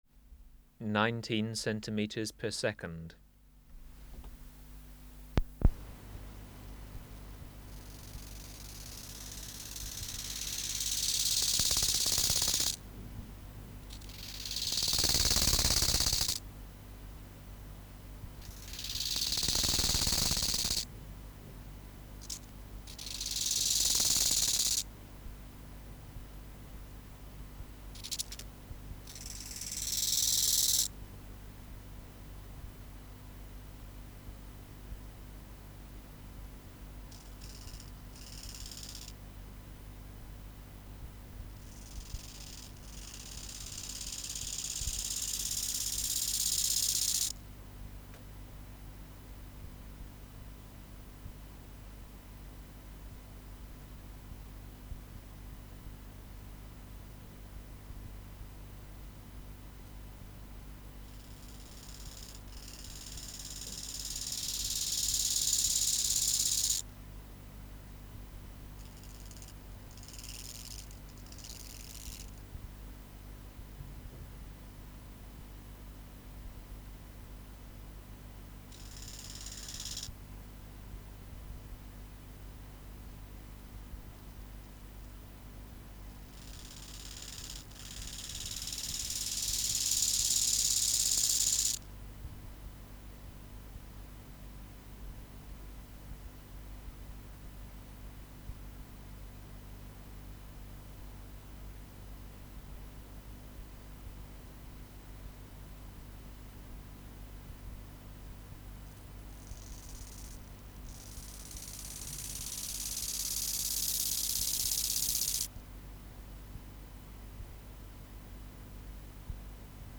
Species: Chorthippus (Glyptobothrus) biguttulus
Recording Location: BMNH Acoustic Laboratory
Reference Signal: 1 kHz for 10 s
Substrate/Cage: Small recording cage Biotic Factors / Experimental Conditions: Courtship, male next to female
Microphone & Power Supply: Sennheiser MKH 405 Distance from Subject (cm): 10 Filter: Low Pass, 24 dB per octave. Corner frequency 20 Hz
Recorder: Kudelski Nagra IV D (-32dB at 50 Hz)